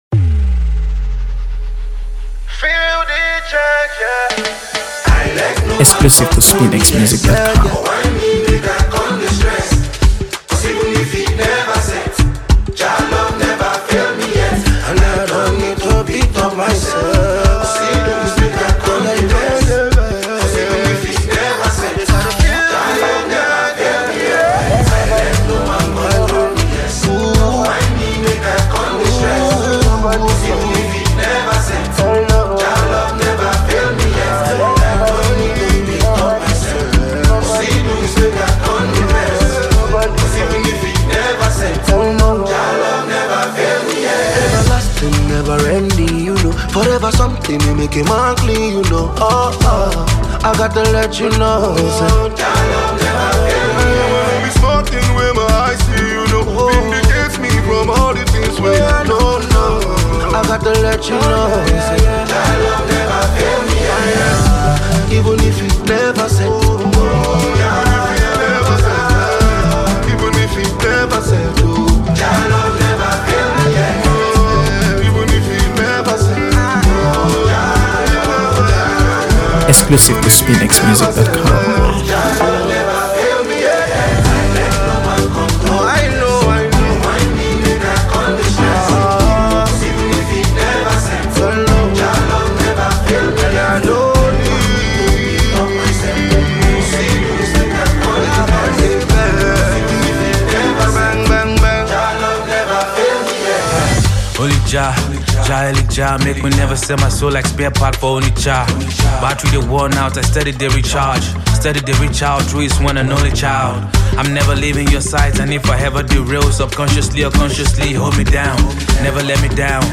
AfroBeats | AfroBeats songs
Soulful